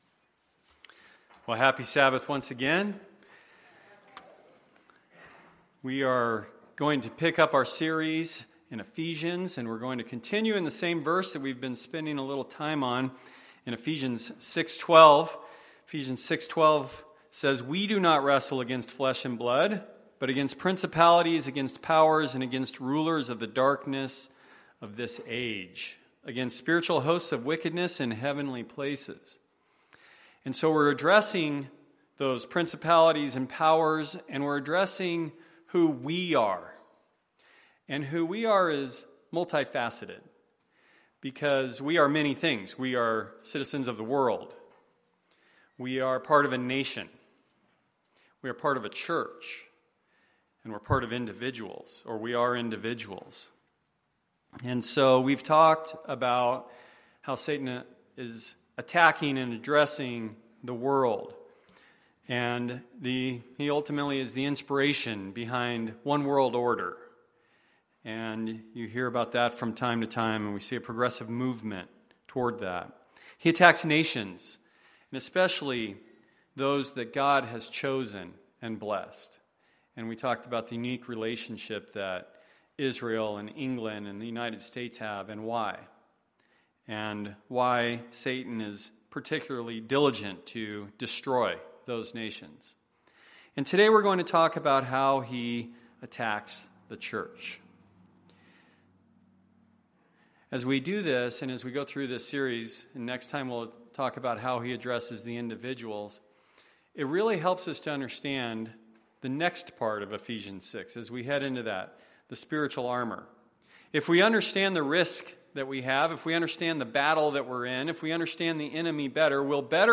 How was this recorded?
Given in Phoenix Northwest, AZ